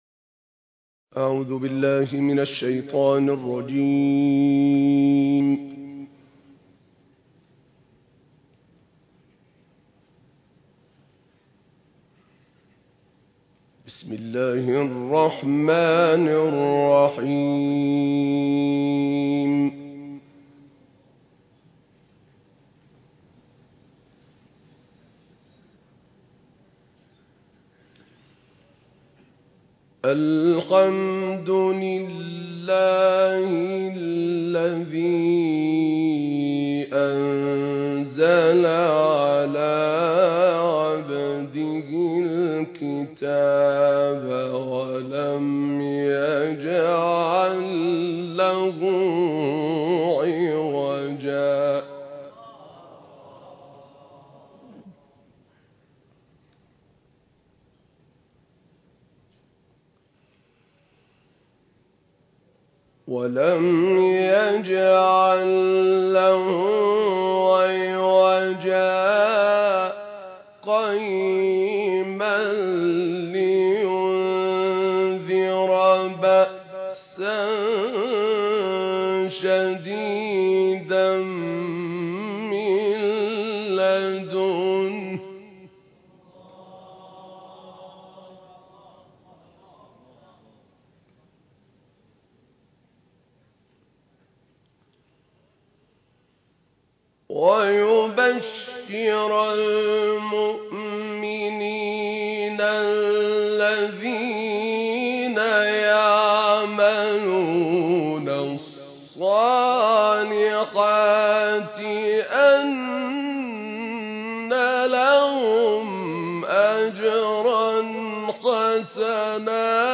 تلاوت سوره مبارکه
تلاوت سوره مبارکه "کهف" استاد عبدالفتاح ‌طاروطی اجراشده در شهر اراک منتشر شد.
تلاوت‌های عبدالفتاح طاروطی متأثر از تلاوتهای استاد شحات محمد‌ انور است.